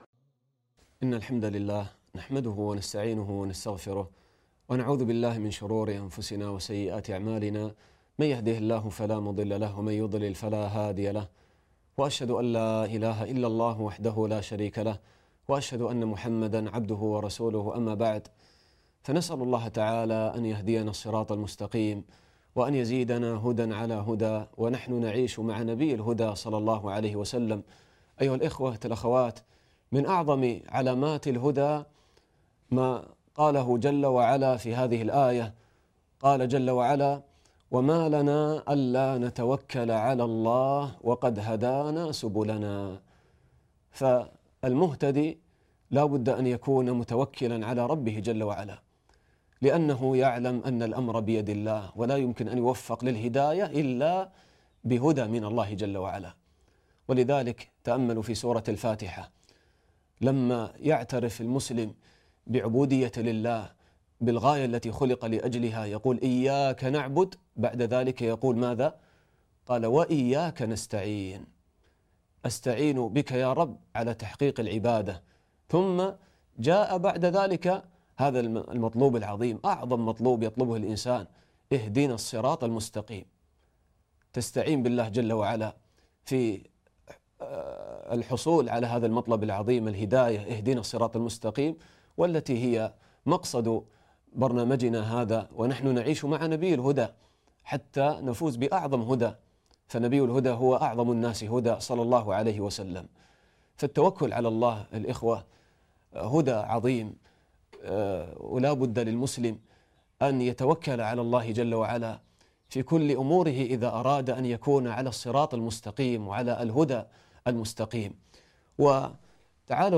الدرس الرابع عشر